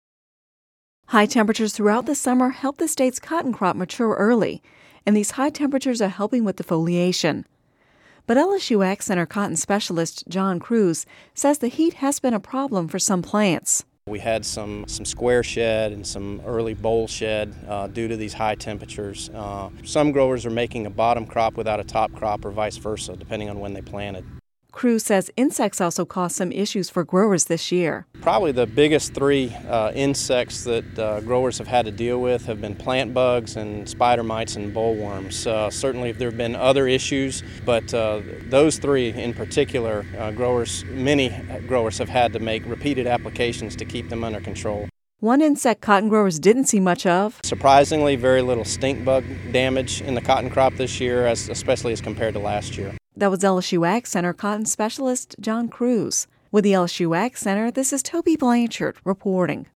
Radio News